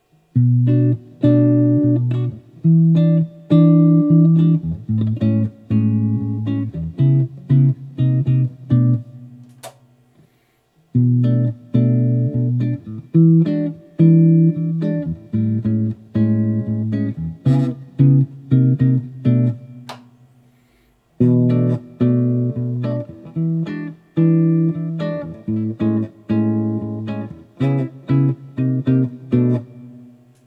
Finally, I did the unthinkable and recorded myself abusing both guitars with my wretched finger-style playing.
I could have likely backed off the boominess of both of them by removing the speaker from the floor but that seemed an awful lot like real work so I elected to pass.